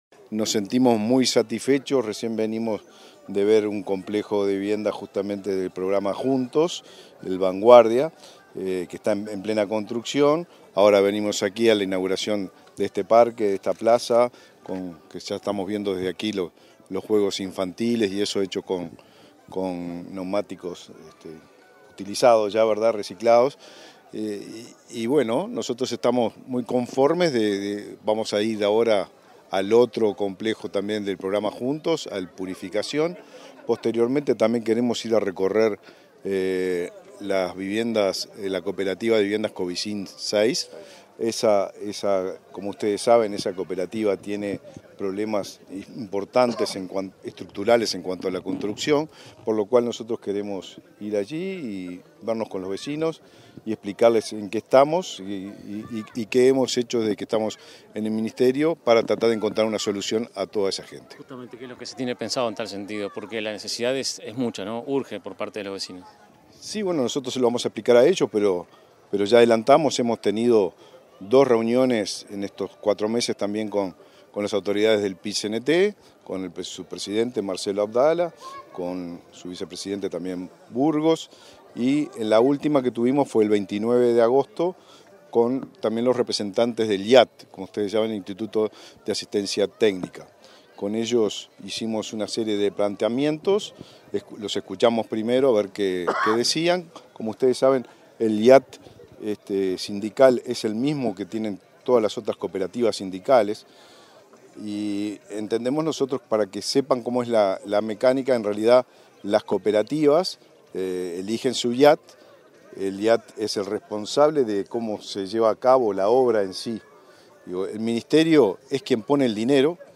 Declaraciones del Ministro de Vivienda y Ordenamiento Territorial, Raúl Lozano
Declaraciones del Ministro de Vivienda y Ordenamiento Territorial, Raúl Lozano 21/09/2023 Compartir Facebook X Copiar enlace WhatsApp LinkedIn Tras la inauguración del espacio de juegos infantiles Cimarrón III, en Río Negro, este 21 de setiembre, el ministro de Vivienda y Ordenamiento Territorial, Raúl Lozano realizó declaraciones a la prensa.
lozano prensa.mp3